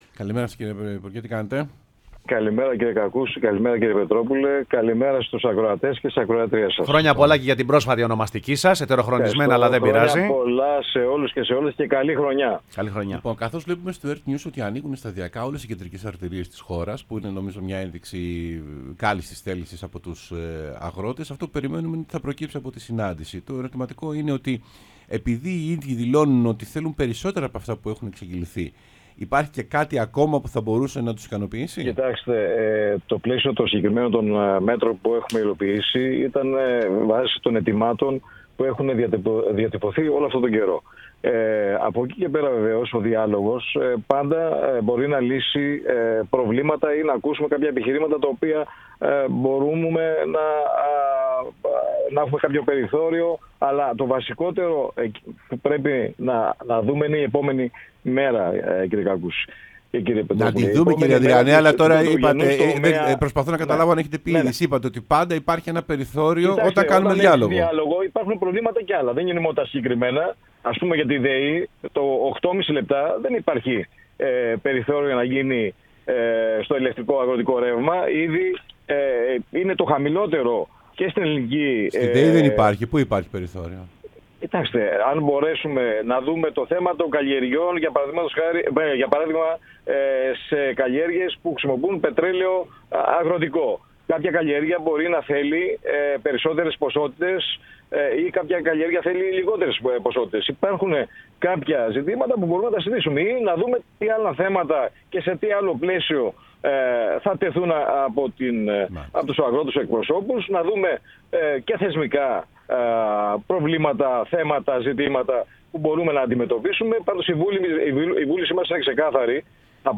Γιάννης Ανδριανός, Υφυπουργός Αγροτικής Ανάπτυξης και Τροφίμων, μίλησε στην εκπομπή Σεμνά και ταπεινά